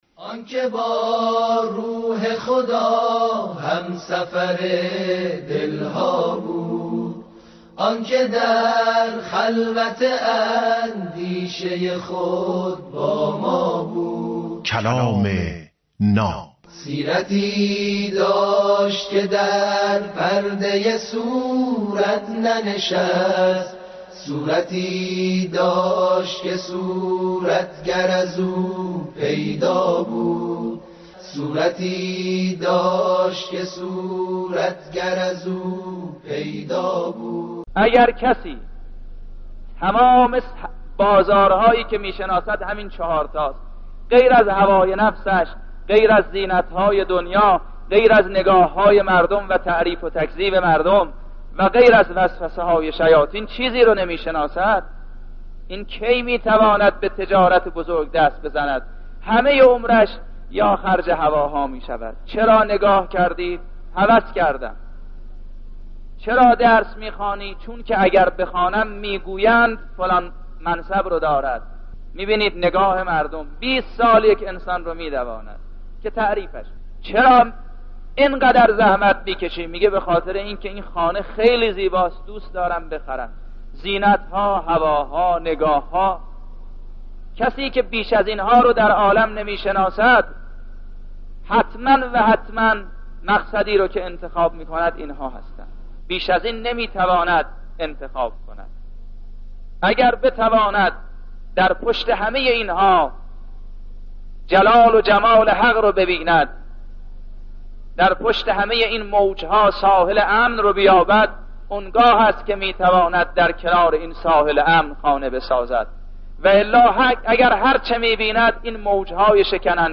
کلام ناب برنامه ای از سخنان بزرگان است